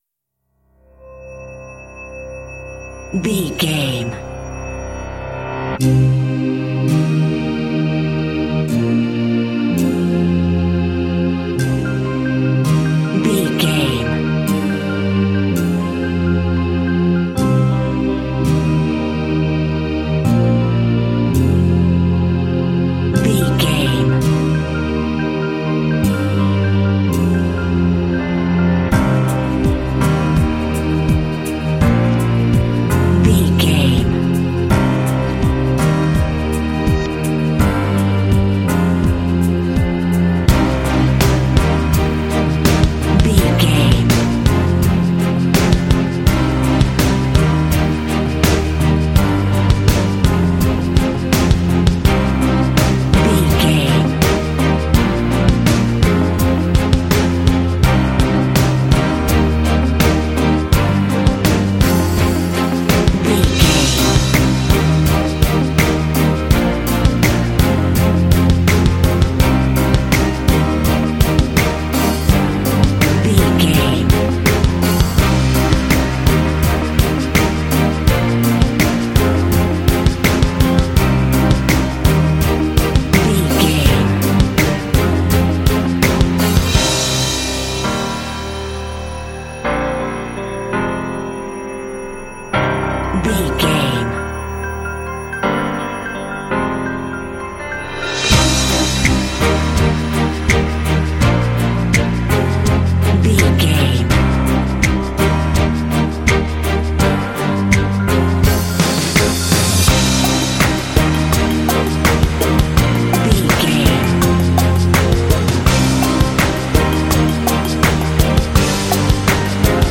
Epic / Action
Aeolian/Minor
powerful
inspirational
synthesiser
piano
strings
percussion
drums
symphonic rock
cinematic
classical crossover